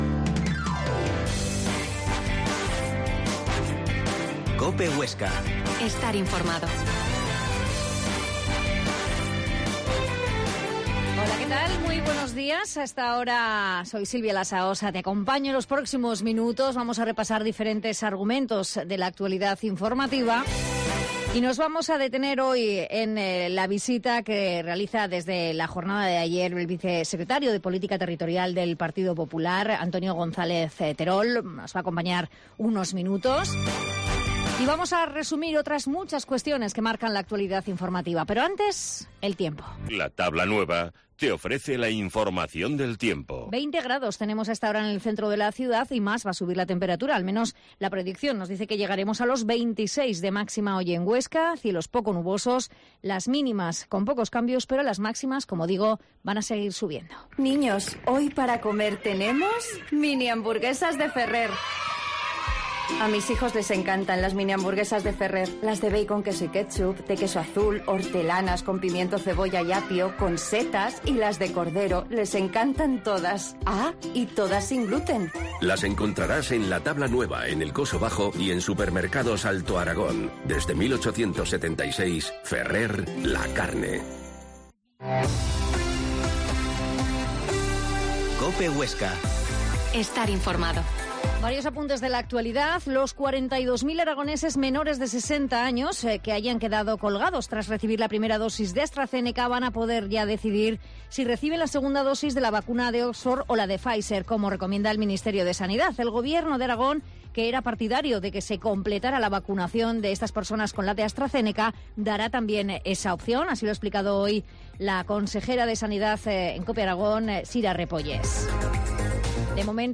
Herrera en Cope Huesca 12,50h. Entrevista al vicesecretario de Política Territorial del PP